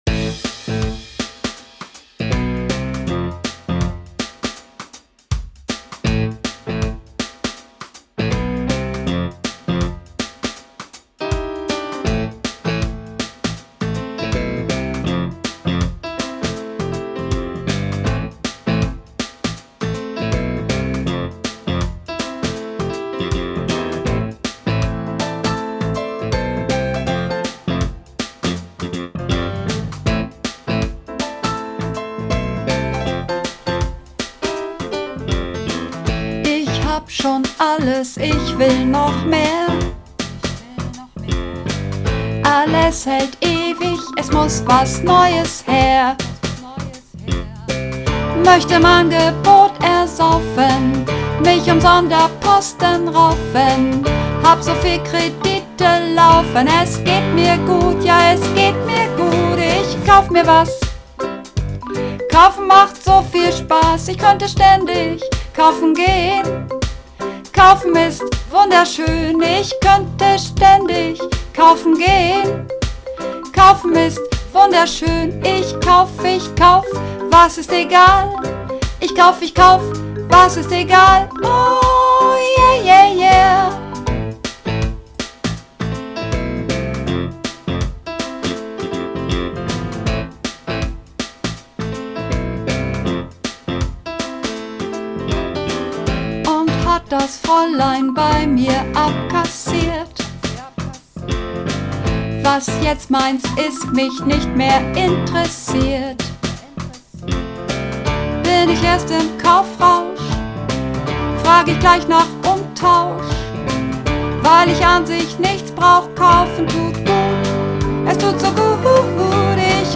Übungsaufnahmen - Kaufen
Kaufen (Sopran)
Kaufen__3_Sopran.mp3